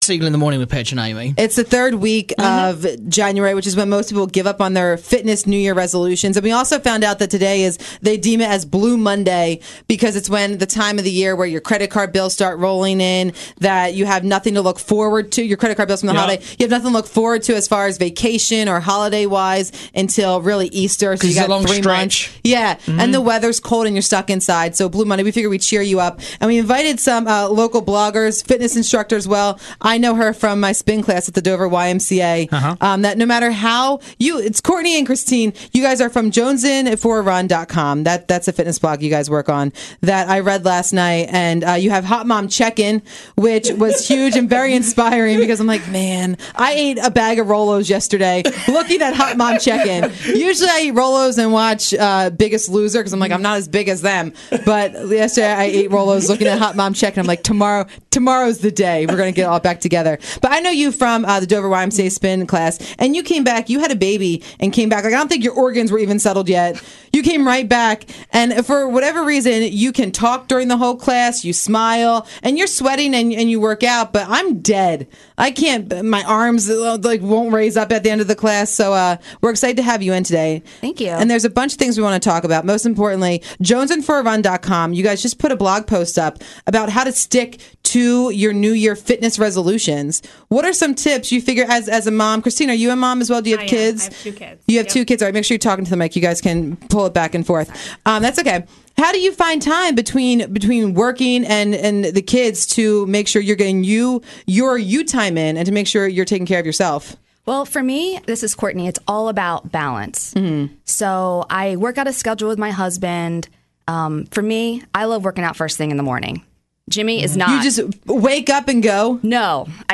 came on air to talk about how to stick to those healthy goals.